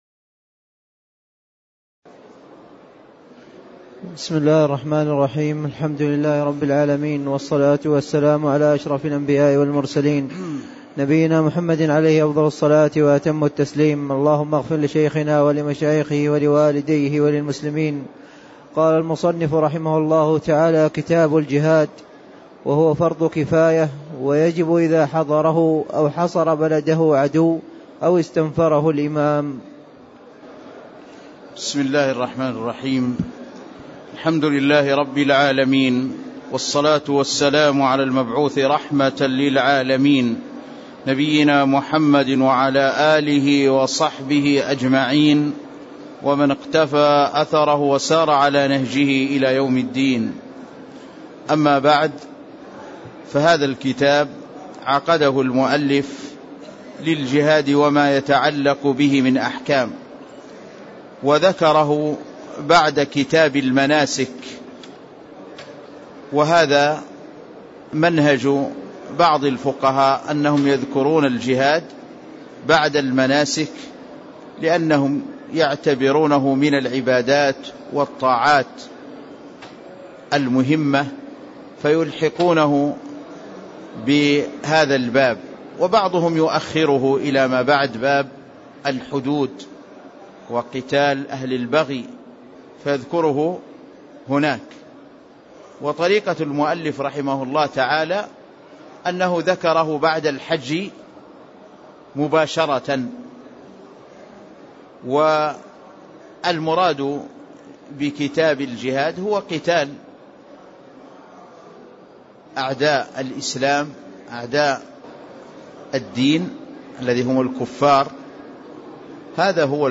تاريخ النشر ٢٤ جمادى الأولى ١٤٣٦ هـ المكان: المسجد النبوي الشيخ